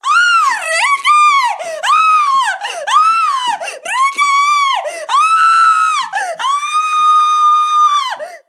Chica gritando a sus ídolos (fan)
gritar
Sonidos: Acciones humanas
Sonidos: Voz humana